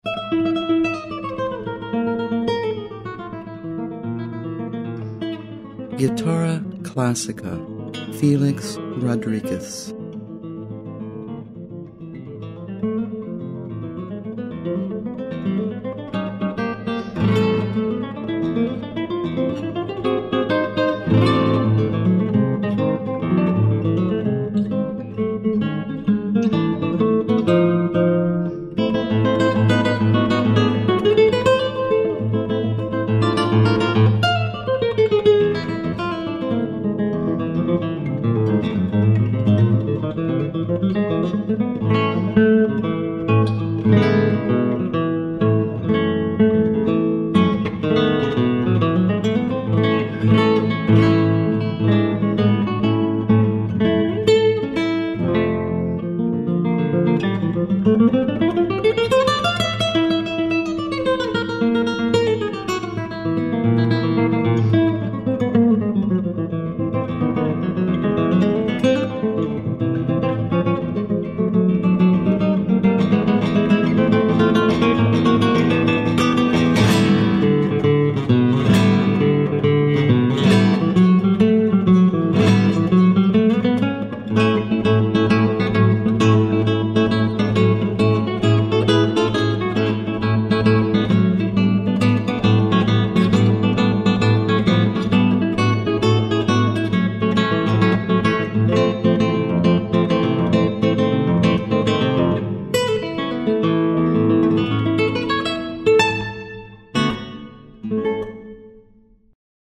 классической гитары
виртуозный гитарист из Пуэрто-Рико